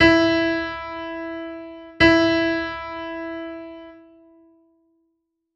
Audio nota MI